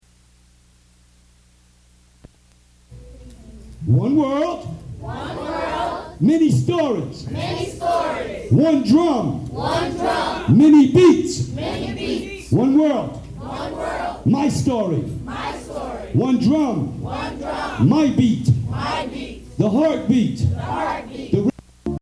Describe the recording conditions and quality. Live recordings from Stetson!